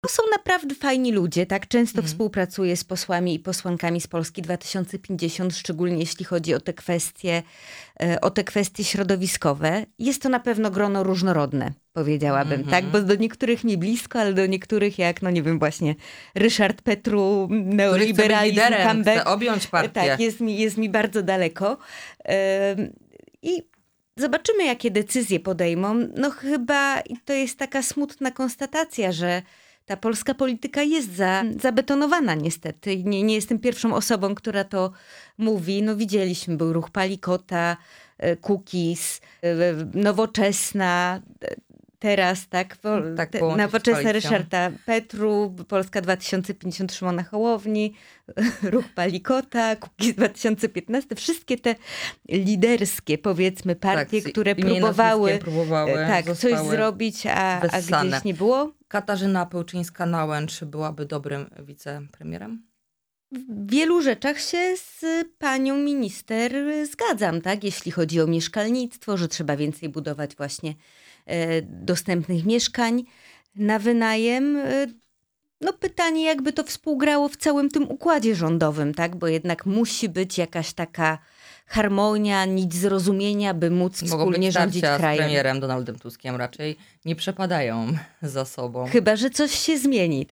Posłanka na Sejm Małgorzata Tracz byłą naszym „Porannym Gościem”.